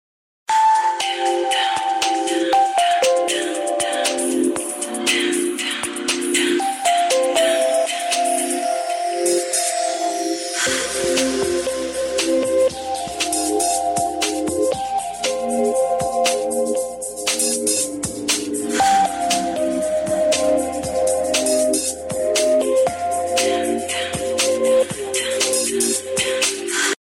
test_left.mp3